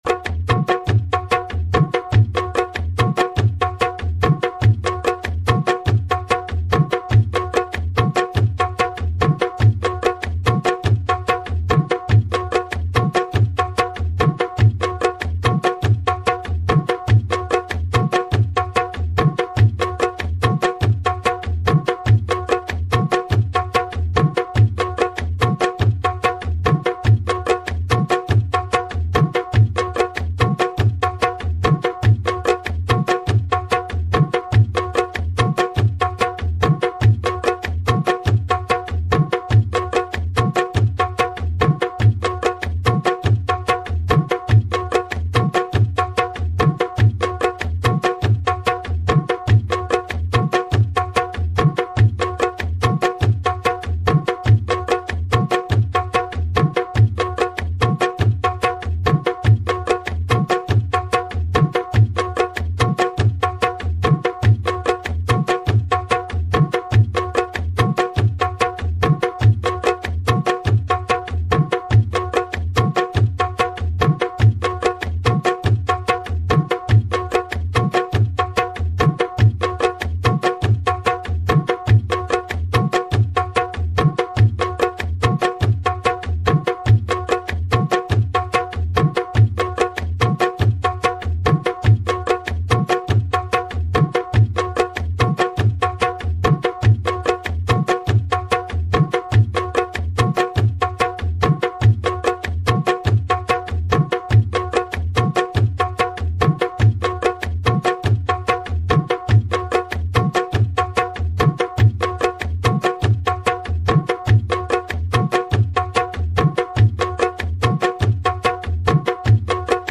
DOWNLOADING Dadra Kahrwa MUSIC LOOP PLEASE WAIT......